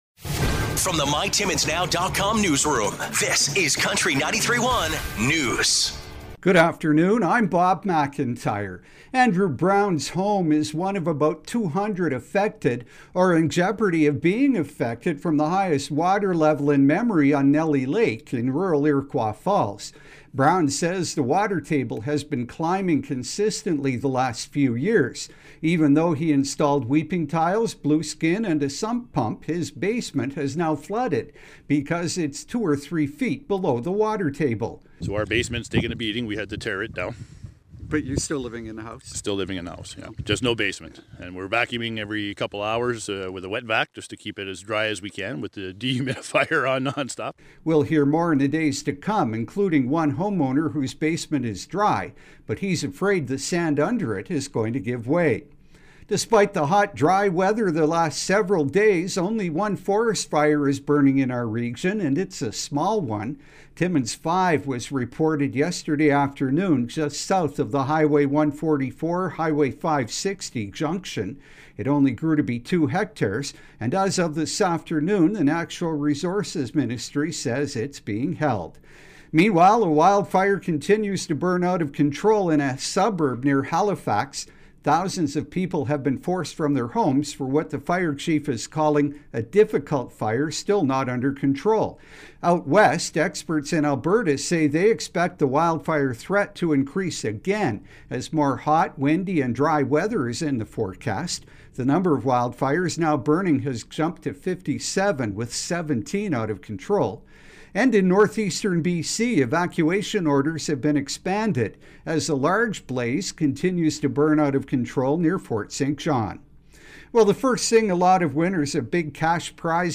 5:00pm Country 93.1 News – Mon., May 29, 2023